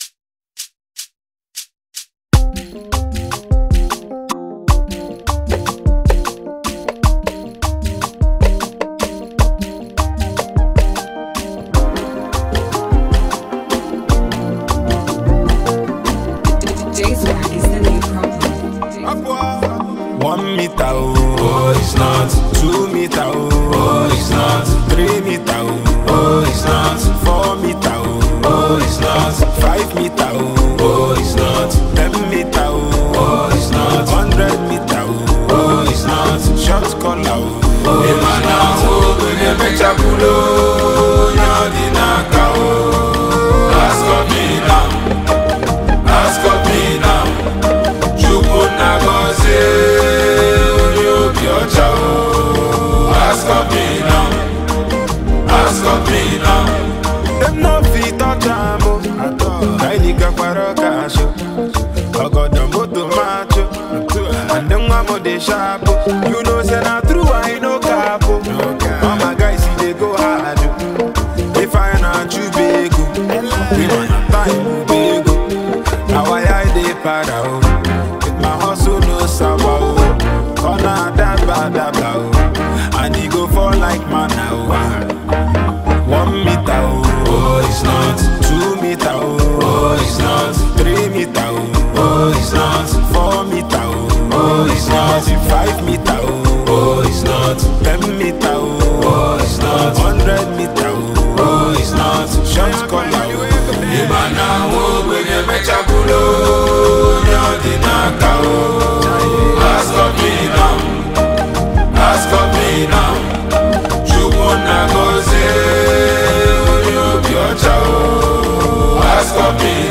Talented Nigerian rapper